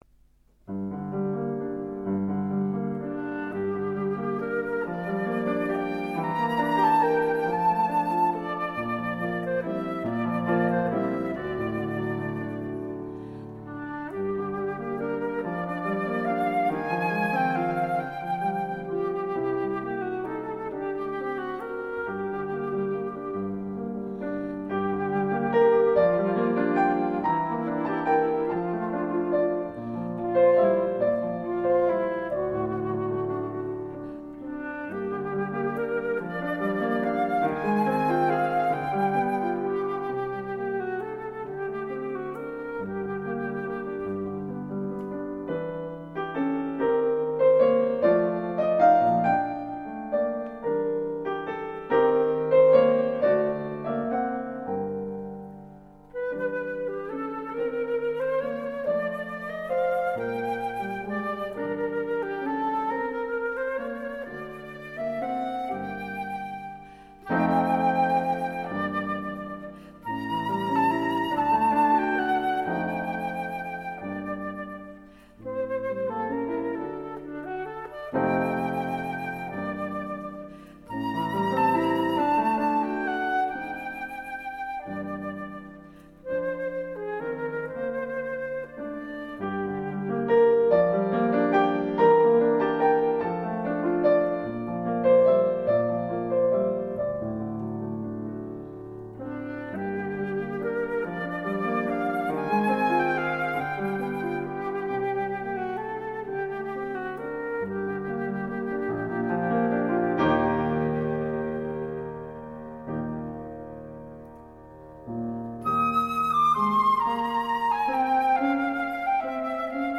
小品式的音乐，长笛、钢琴和竖琴的组合
录音的音色也是赏心悦耳
它的音色柔美，金属光泽中透出一种人性化的特质
她的演奏风格是女性独有的纤巧流畅
这三件乐器的录音效果则非比寻常，声部的平衡感和音质的透彻感绝可媲美世界同类制作。